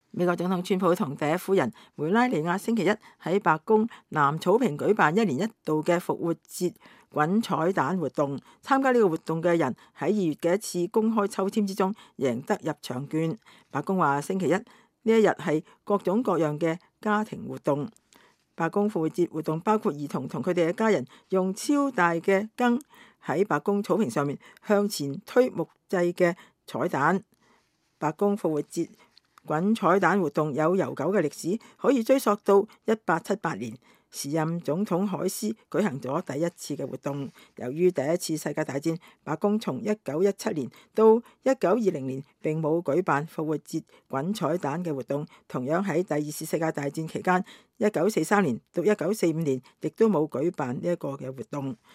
美國總統川普和第一夫人梅拉尼亞星期一在白宮南草坪舉辦一年一度的復活節滾彩蛋活動。